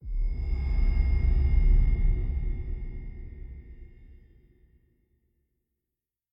Song: SFX anna awaken 1 impact